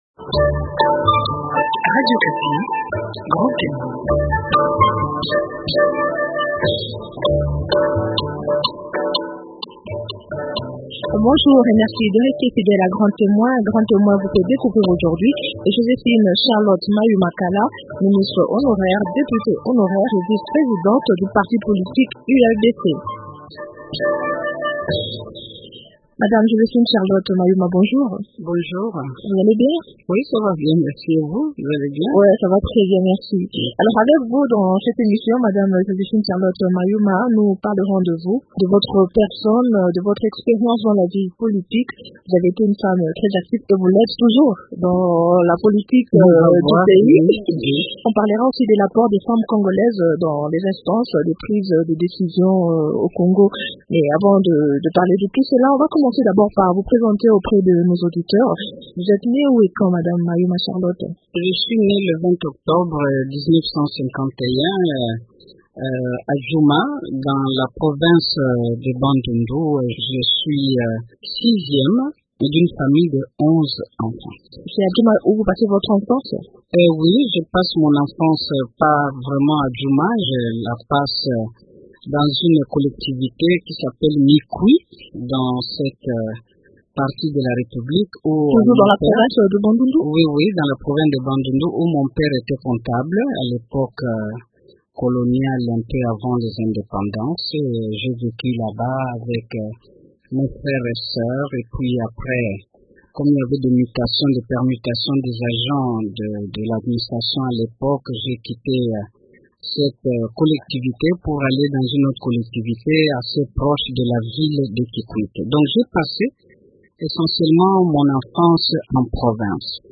Joséphine Charlotte Mayuma Kala, ministre honoraire à la condition féminine et famille, député honoraire et vice présidente du parti politique Union des Libéraux Démocrates Chrétiens (ULDC), est celle que Grand témoin reçoit pour vous aujourd’hui.
Découvrez aussi dans cet entretien sa passion pour les fleurs et la salsa.